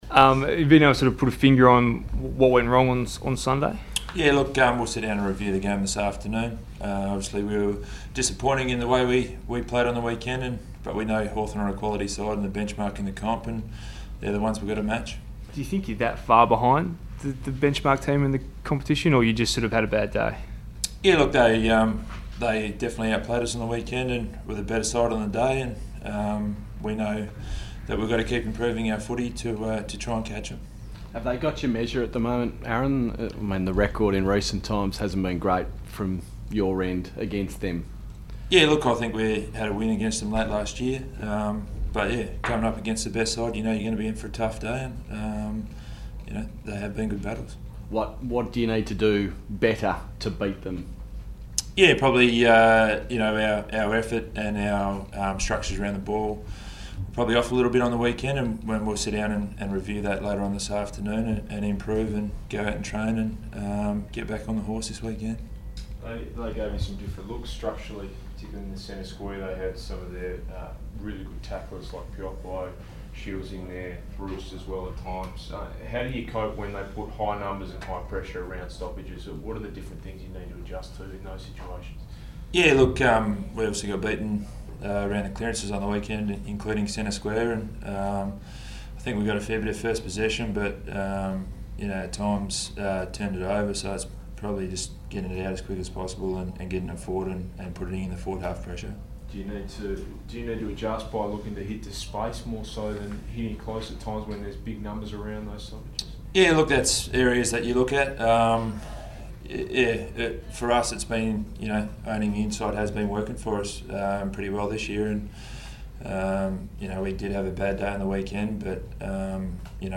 Aaron Sandilands media conference - Tuesday 14 July
Aaron Sandilands speaks to the media following the loss to Hawthorn